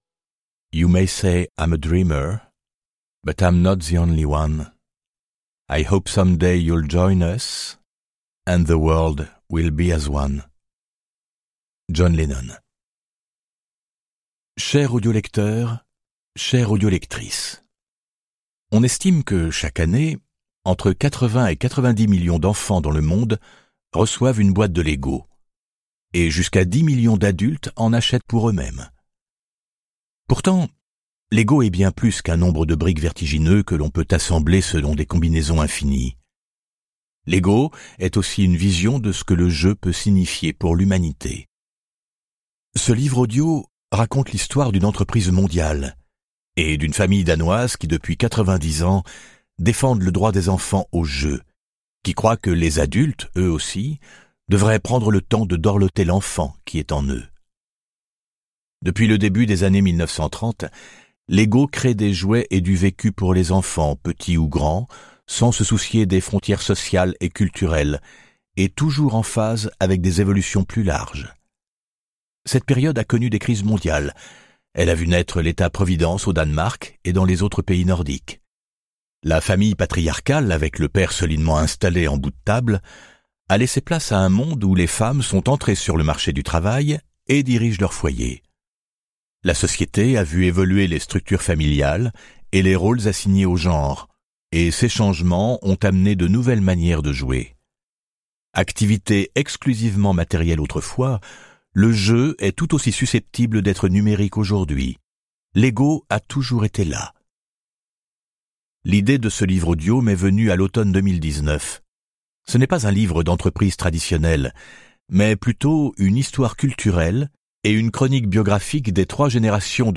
je découvre un extrait - La saga Lego de Jens Andersen
Ce livre audio raconte l'extraordinaire histoire d'une famille danoise et d'une entreprise mondiale qui, depuis 90 ans, défendent le droit des enfants à jouer et encouragent les adultes à nourrir leur enfant intérieur.